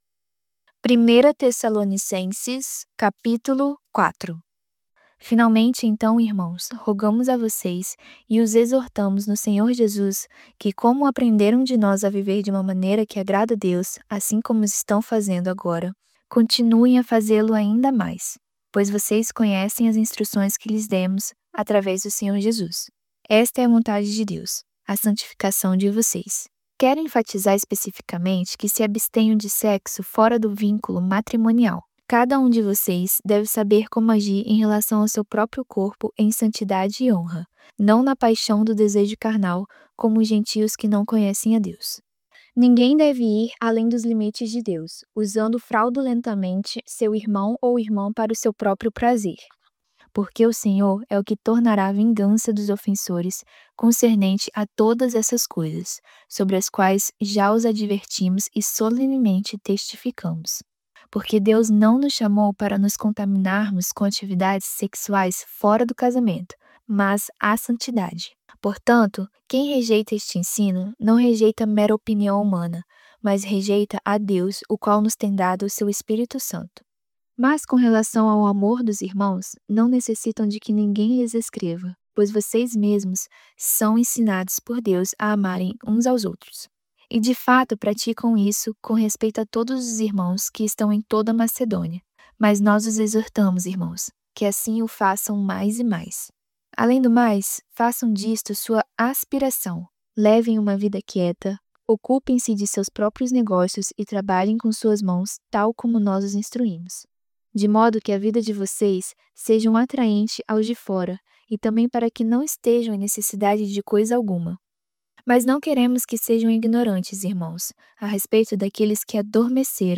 voz-audiobook-novo-testamento-vida-do-pai-primeira-tessalonicenses-capitulo-4.mp3